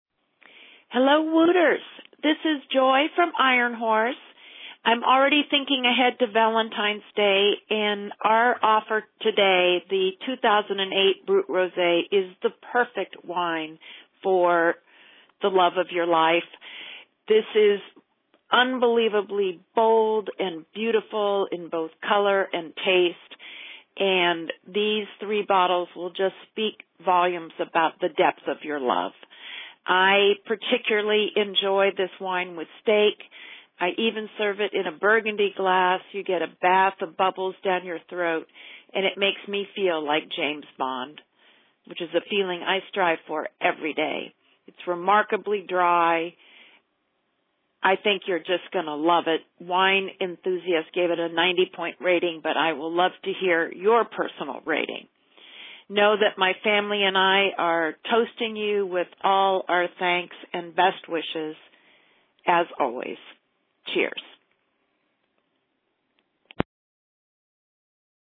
Vintner Voicemail